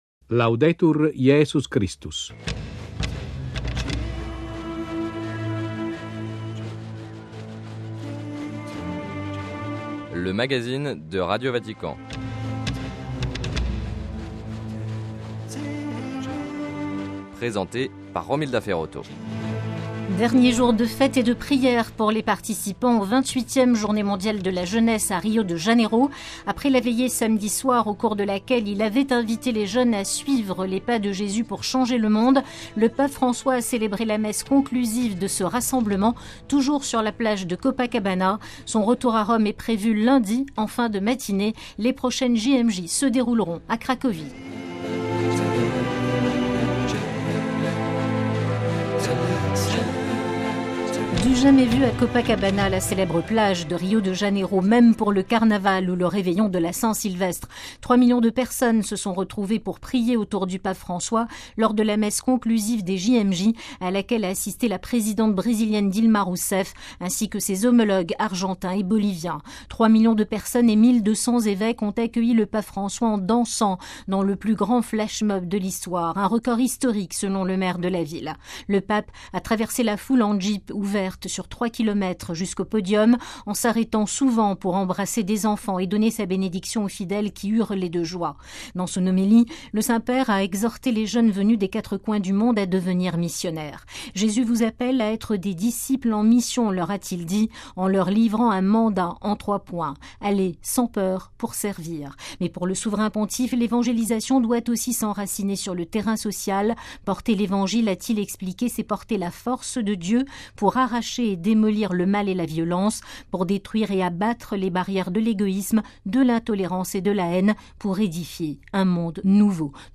Sommaire : - Dernière journée des JMJ à Rio de Janeiro ; les prochaines se dérouleront à Cracovie, en 2016 : compte-rendu, reportages, témoignages, interviews et commentaires. - Décès du plus âgé des cardinaux, l'italien Ersilio Tonini.